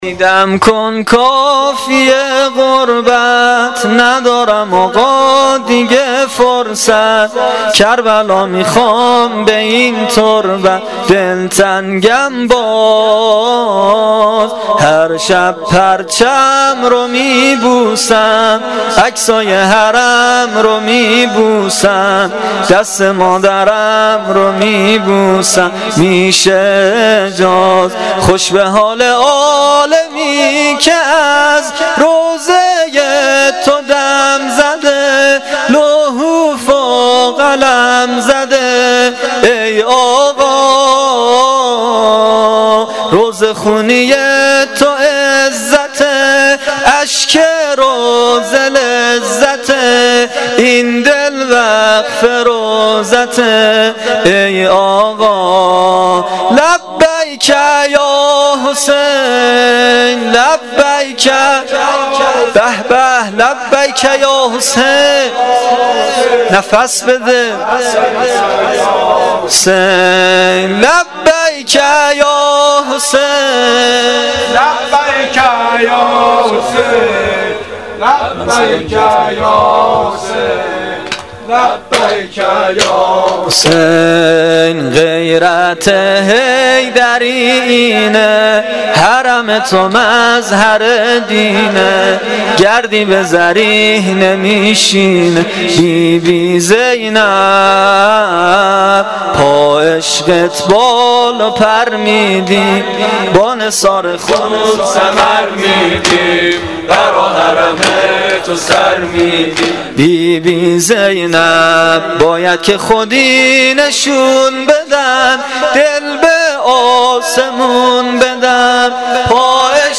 سرود پایانی شب نهم محرم الحرام 1396 (شب تاسوعا)